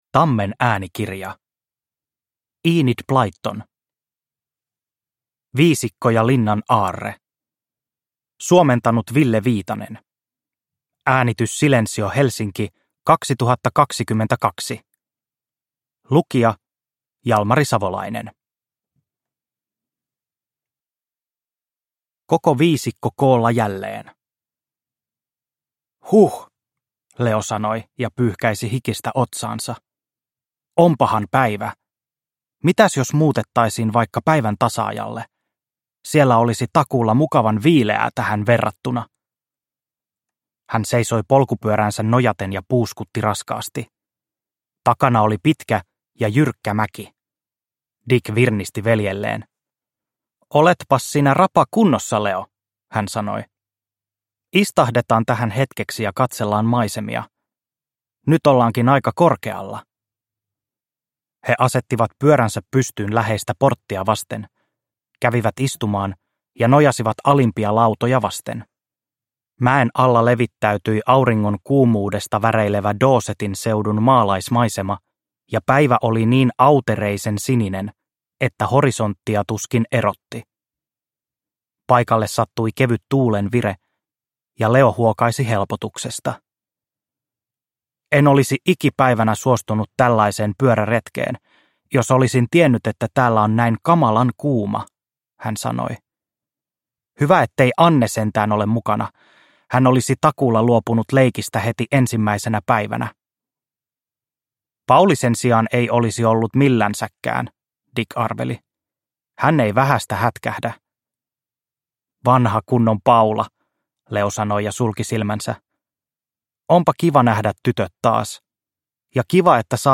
Viisikko ja linnan aarre – Ljudbok – Laddas ner